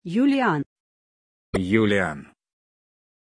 Pronunciation of Julián